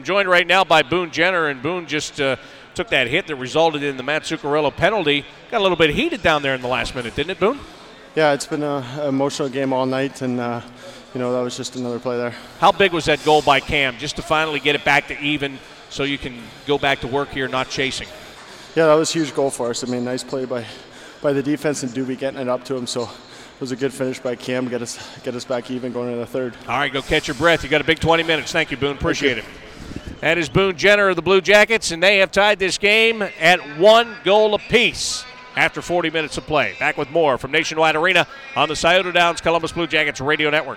Boone Jenner Interview
during the second intermission